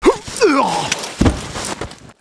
带男声的死亡倒地zth070518.wav
通用动作/01人物/02普通动作类/带男声的死亡倒地zth070518.wav